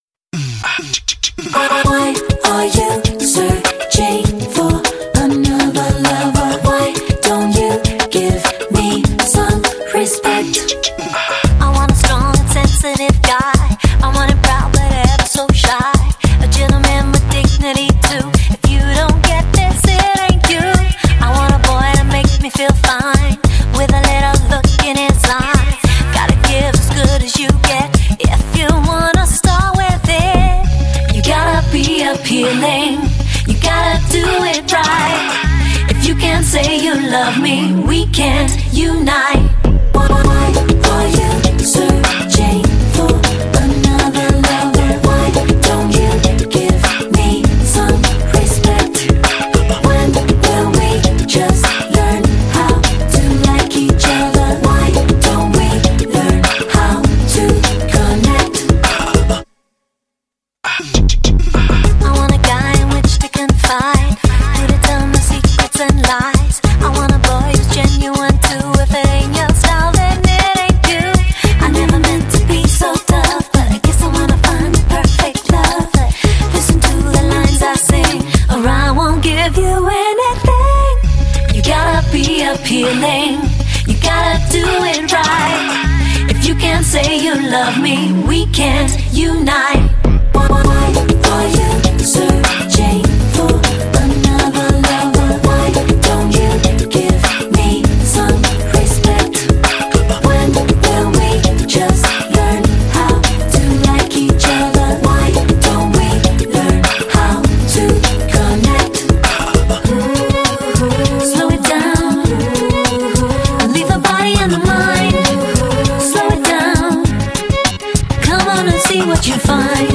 专辑风格：Pop